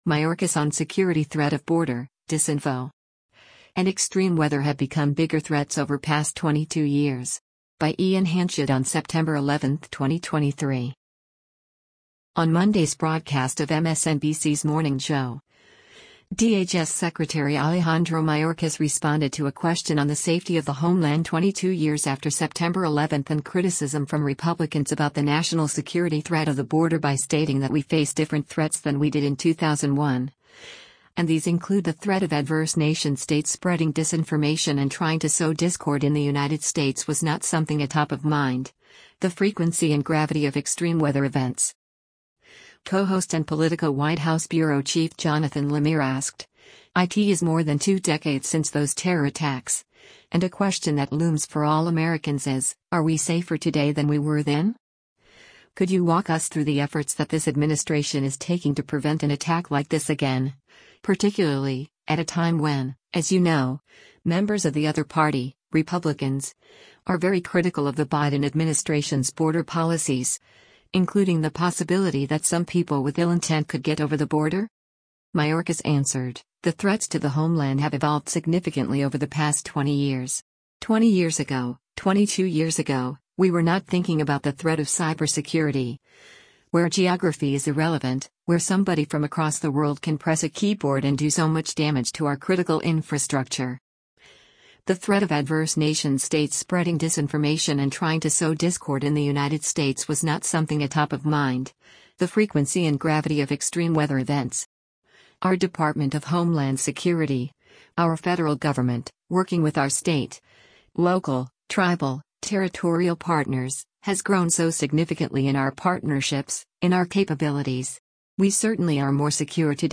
On Monday’s broadcast of MSNBC’s “Morning Joe,” DHS Secretary Alejandro Mayorkas responded to a question on the safety of the homeland 22 years after September 11 and criticism from Republicans about the national security threat of the border by stating that we face different threats than we did in 2001, and these include “The threat of adverse nation-states spreading disinformation and trying to sow discord in the United States was not something atop of mind, the frequency and gravity of extreme weather events.”